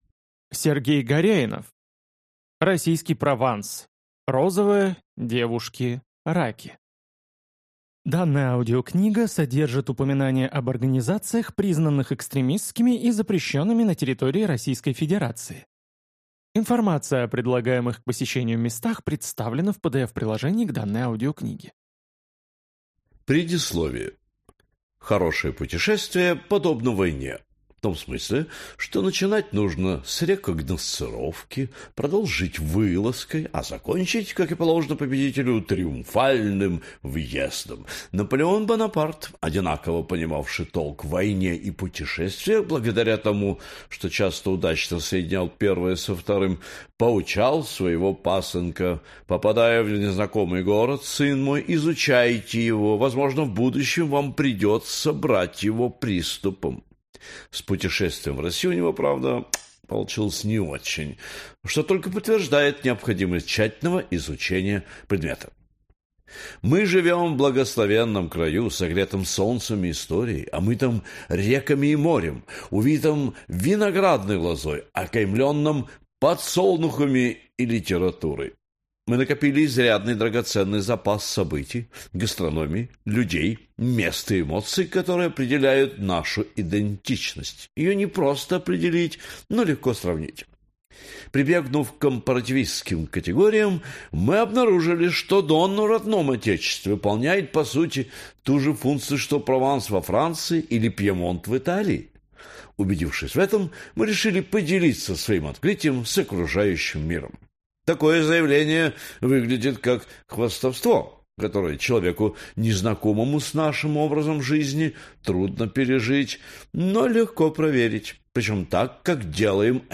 Аудиокнига Российский Прованс: Розовое. Девушки. Раки | Библиотека аудиокниг